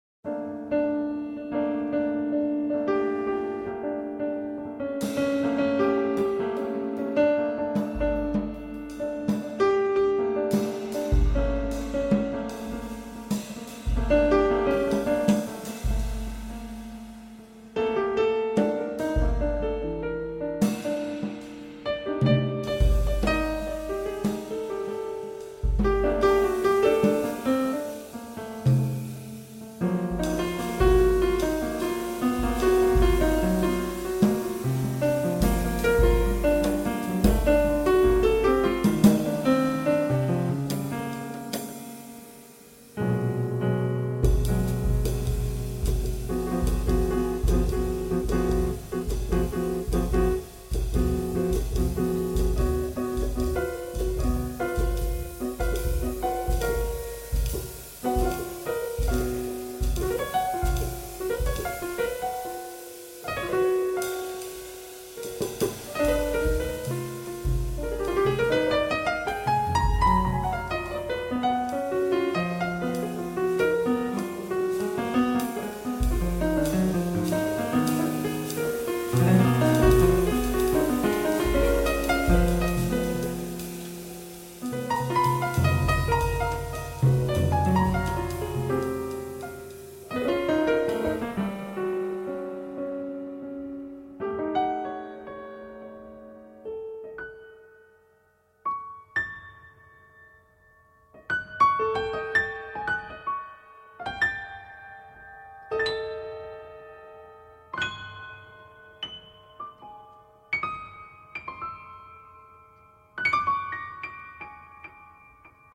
piano
drums